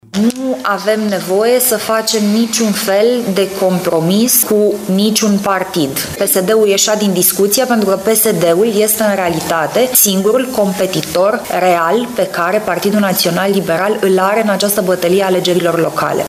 Liberalii nu vor face alianțe preelectorale, a declarat la Brașov și copreşedintele PNL Alina Gorghiu.